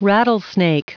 Prononciation du mot rattlesnake en anglais (fichier audio)
Prononciation du mot : rattlesnake